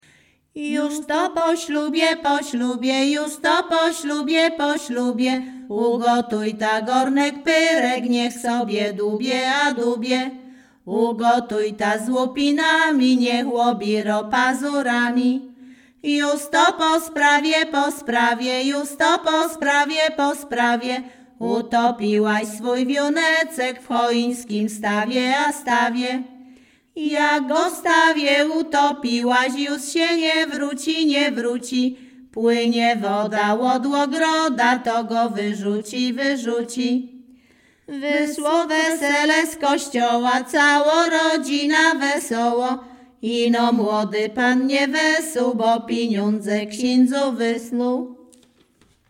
Śpiewaczki z Chojnego
Sieradzkie
Weselna
miłosne weselne wesele przyśpiewki